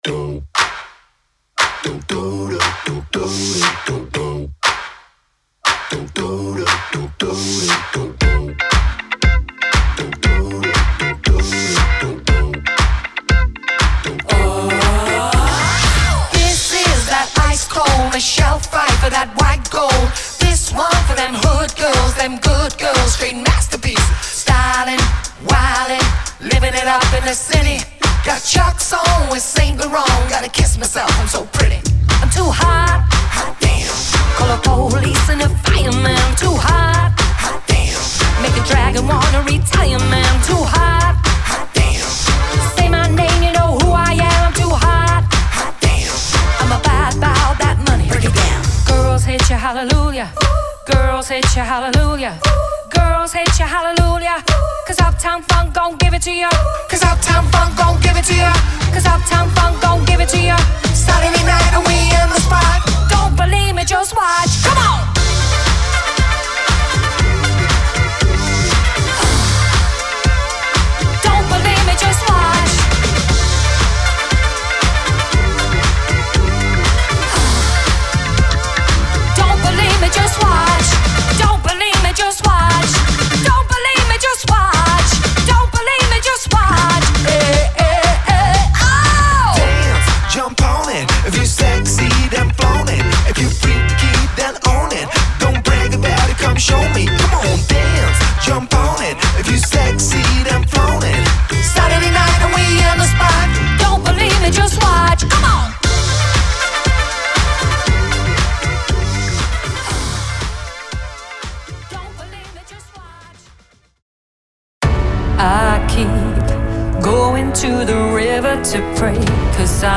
Superb Female Singer